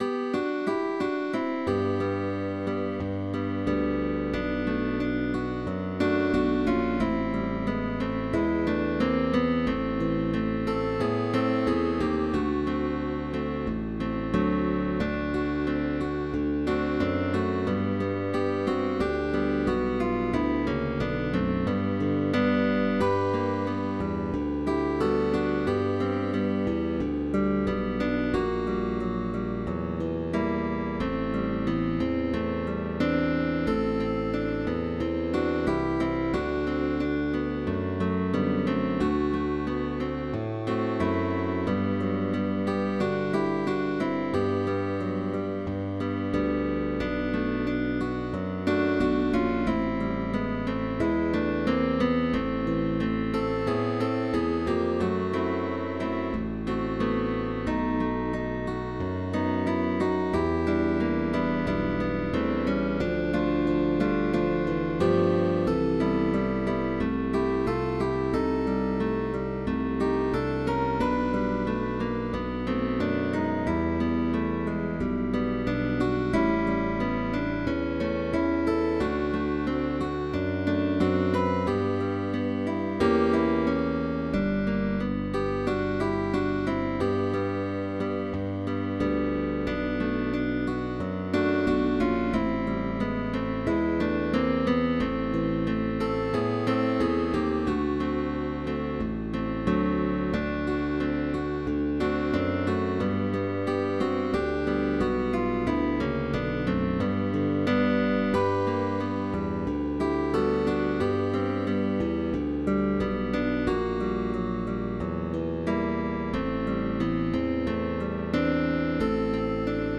with optional bass